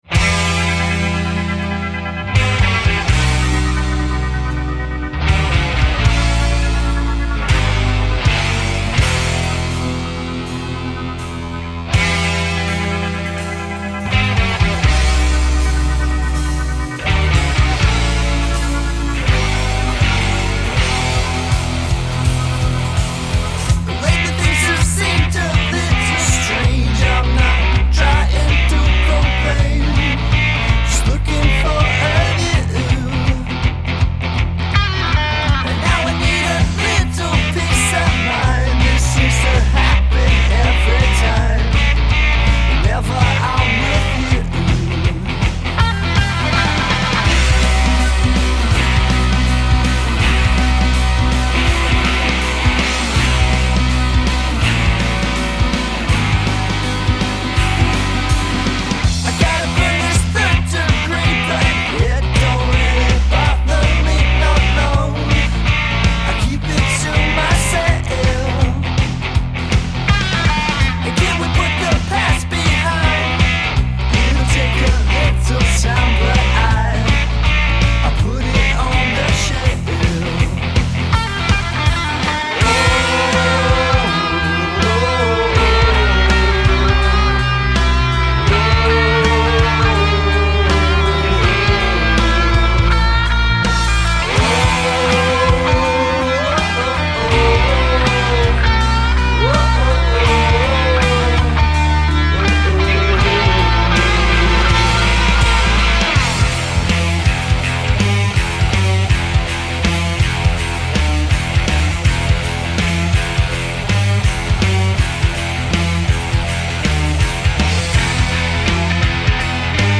dynamic rock band